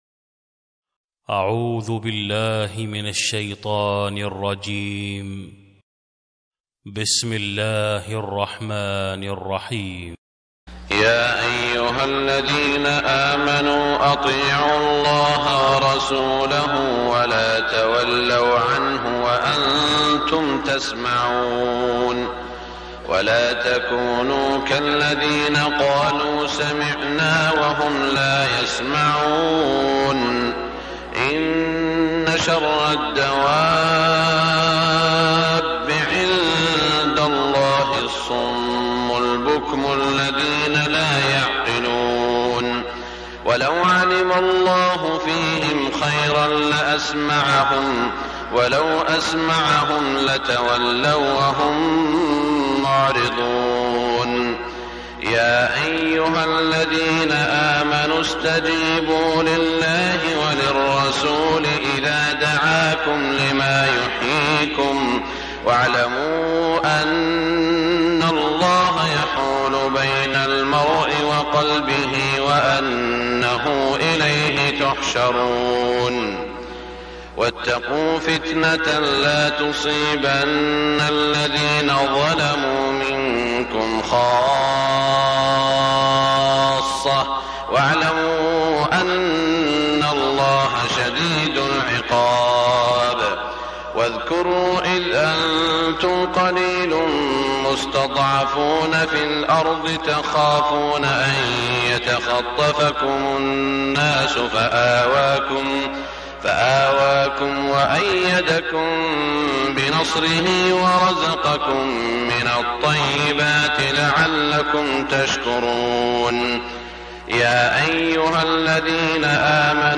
صلاة الفجر شوال 1421هـ من سورة الأنفال 20-37 > 1421 🕋 > الفروض - تلاوات الحرمين